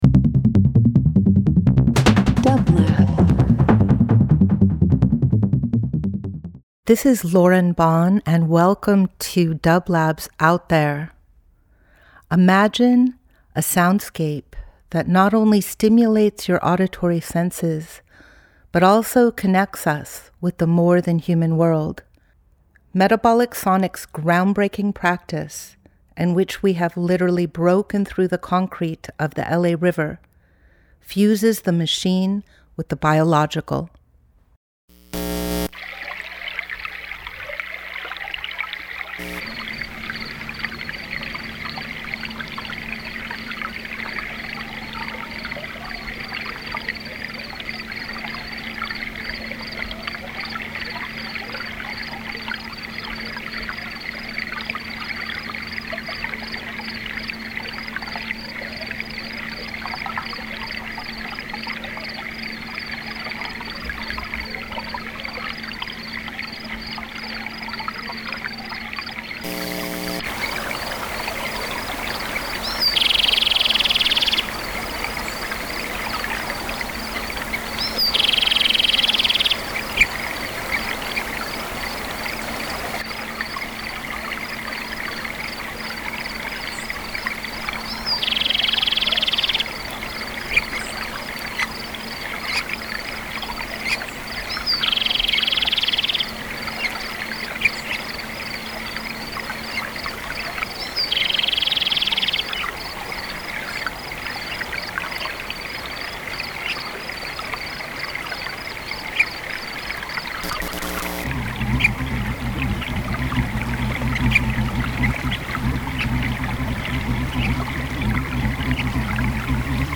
Each week we present field recordings that will transport you through the power of sound. Metabolic Sonics specializes in exploring the captivating sound waves and vibrations of the web of life.
In this week’s episode we listen to a physical sound map of Payahuunadü built by the Metabolic Studio Sonic Division.